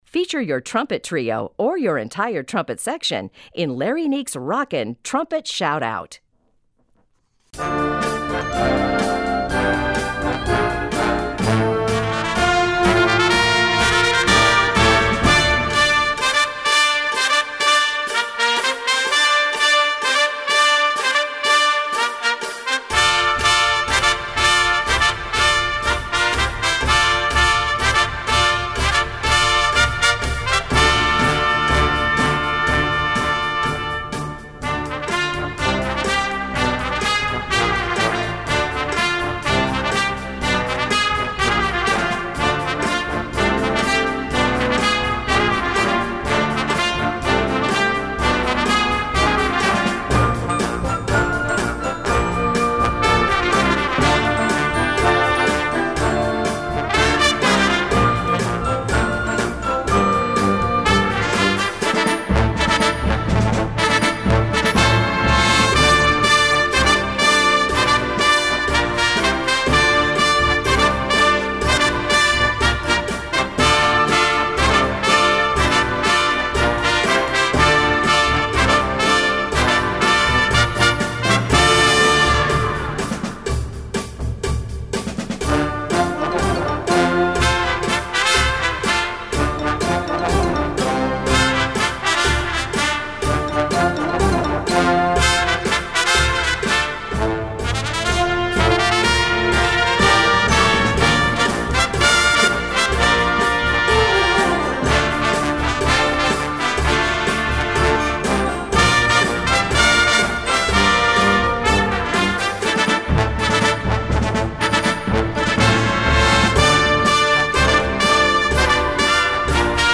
Gattung: Solo für Trompete und Blasorchester
Besetzung: Blasorchester
Mit seinem deutlichen Rock-Anstrich ist